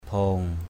/bʱo:ŋ / (t.) đỏ = rouge. red. bhong-rong _BU-_rU đỏ lòm = rouge vif. bhong-bhang _BU-B/ đỏ chói = rouge écarlate. bhong-rong bhong-rah _BU-_rU _BU-rH [Bkt.] đỏ lòe. aw bak jru nyaom...
bhong.mp3